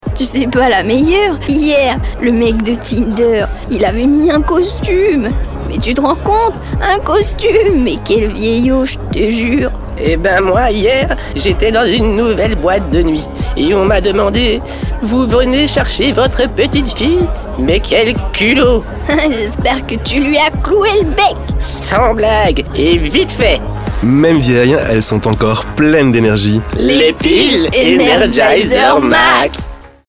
• Campagne radio 30" FR / Client : Energizer
MAQUETTE AUDIO